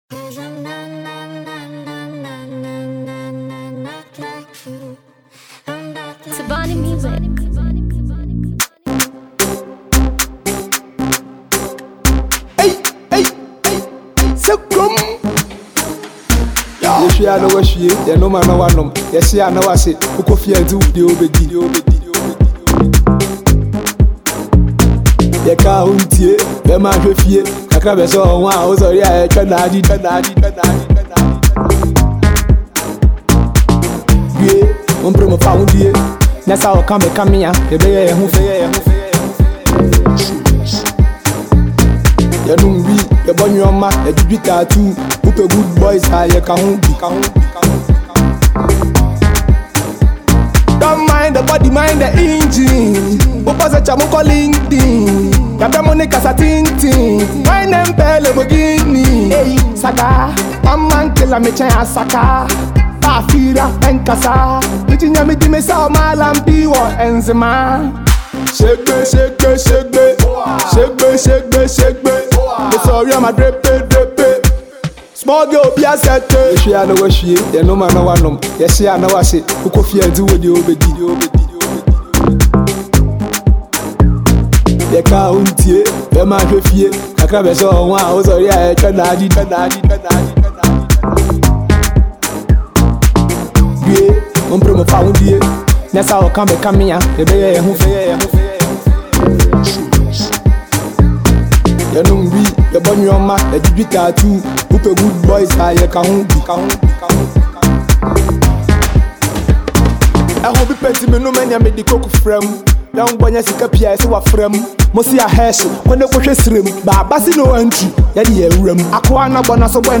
Enjoy this Ghana Afro tune.